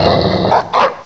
cry_not_stoutland.aif